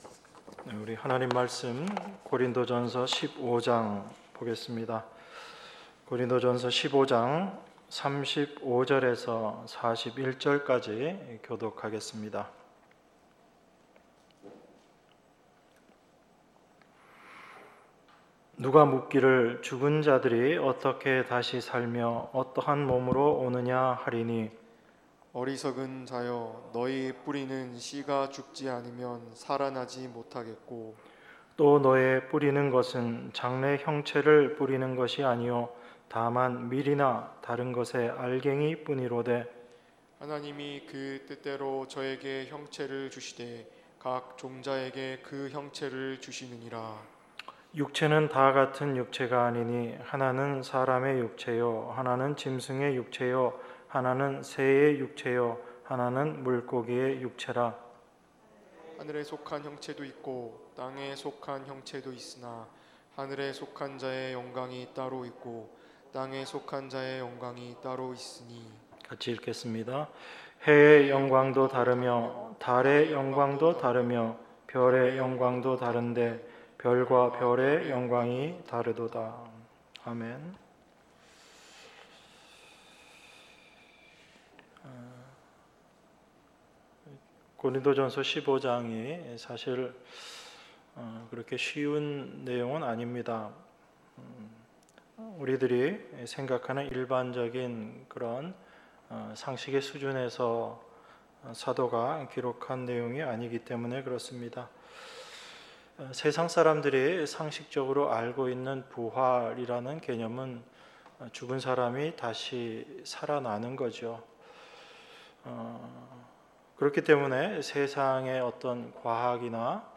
주일1부 고린도전서 15:35~41